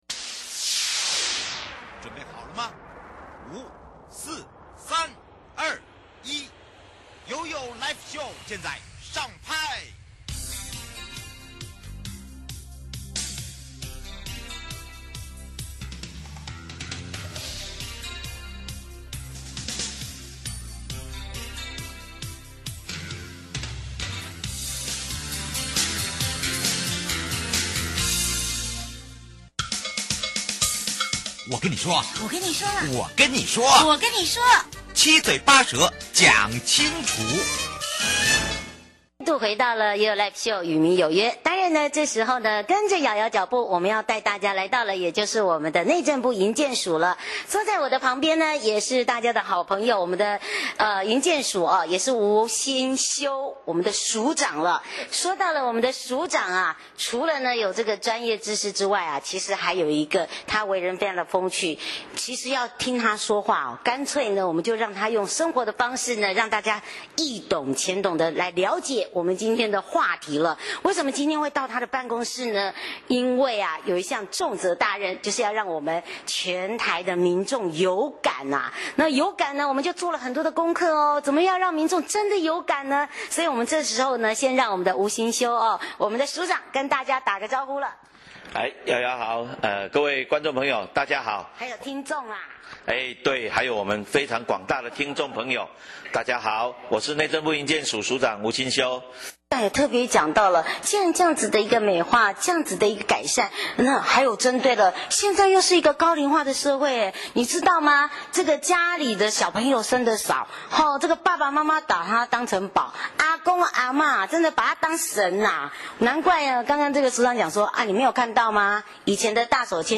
受訪者： 營建你我他 快樂平安行-政府部門除了透過硬體建設外，還有什麼政策配套作為? 請問公共通行權的議題是怎麼發想出來的？